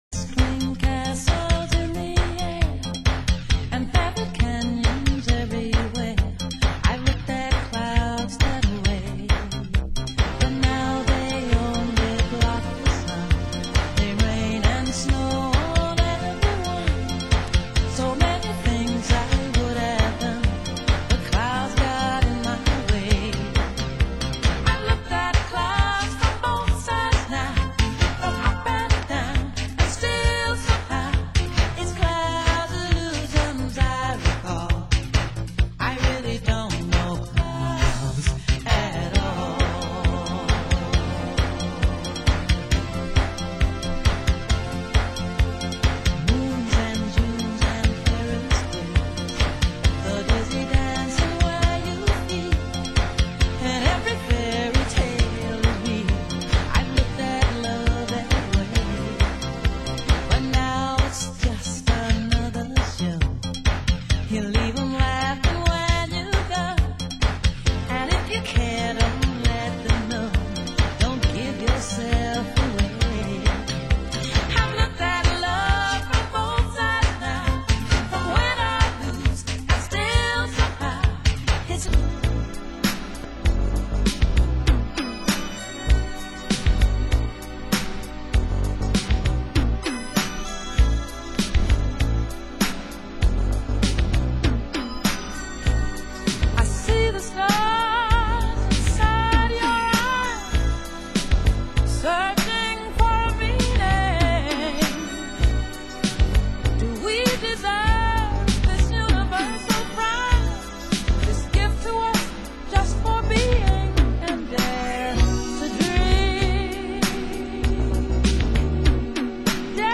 Genre Disco